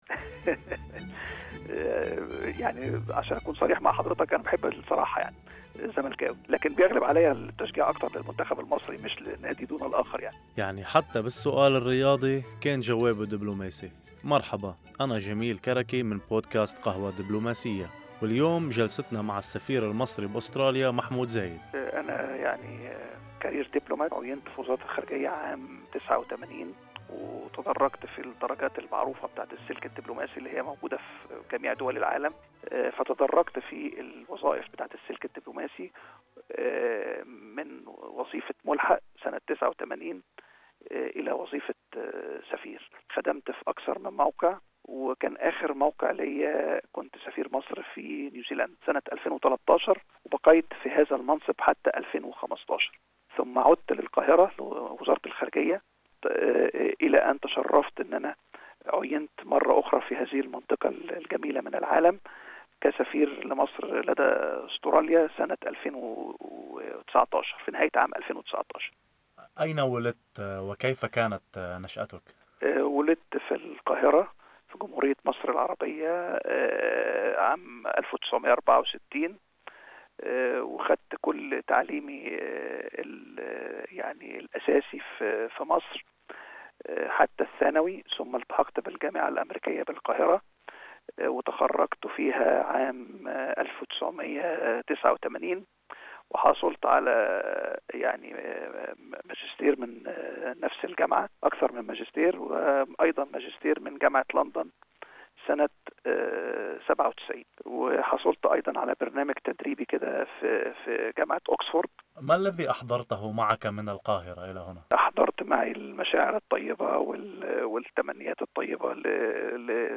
في هذه الحلقة من بودكاست قهوة دبلوماسية نستضيف السفير المصري في أستراليا محمود زايد والذي تدرج في السلك الدبلوماسي وعين في وزارة الخارجية المصرية عام 1989.